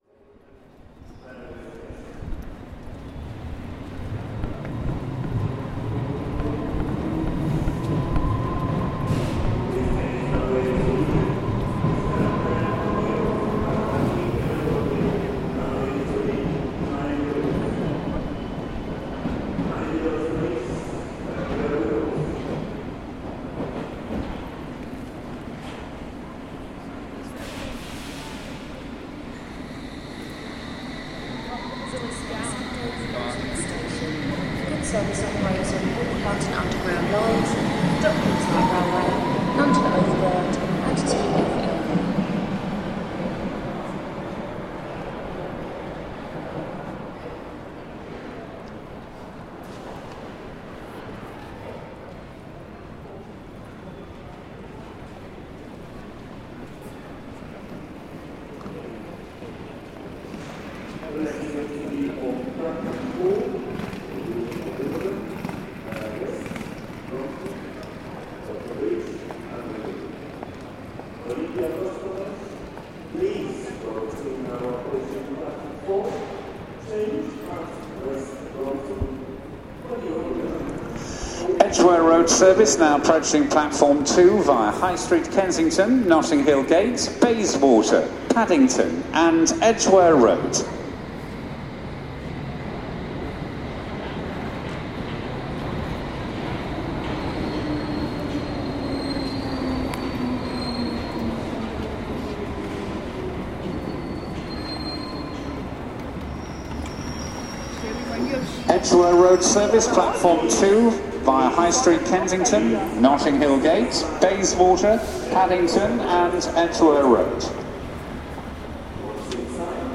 Earl's Court - station ambience, announcements and train arrivals
Field recording from the London Underground by Cities and Memory.